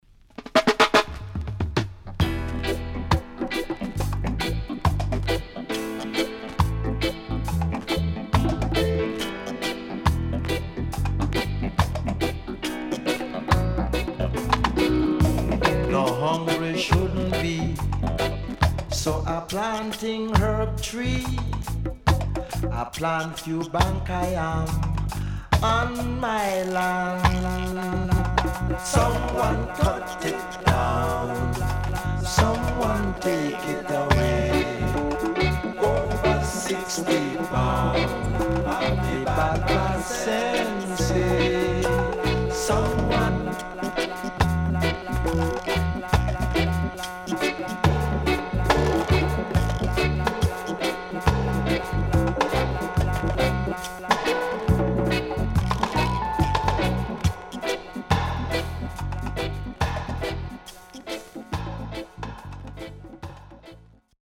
SIDE A:プレス起因によるノイズ入ります。